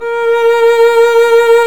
Index of /90_sSampleCDs/Roland L-CD702/VOL-1/STR_Violin 1-3vb/STR_Vln1 _ marc
STR VLN MT0E.wav